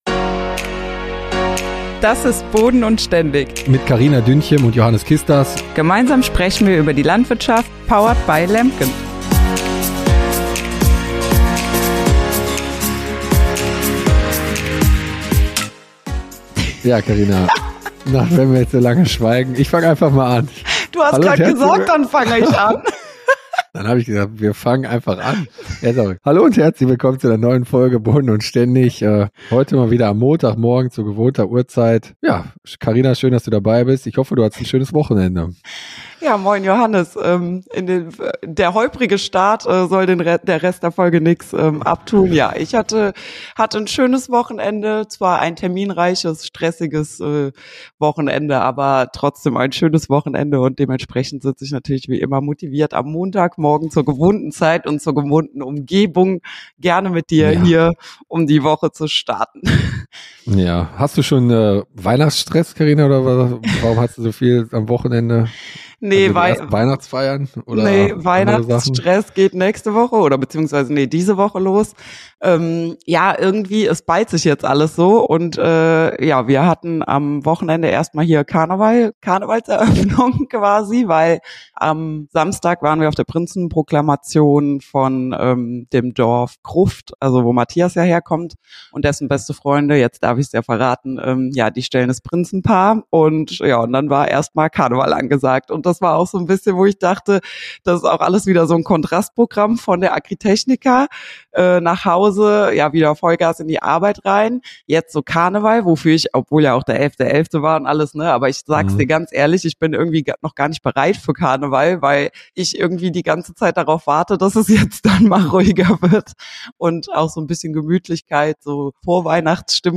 #113 – Volle Kalender, heisere Stimmen – Nach der Agritechnica ist vor dem Jahresendspurt ~ Boden&ständig Podcast
Kein Gast, aber viel Gesprächsstoff.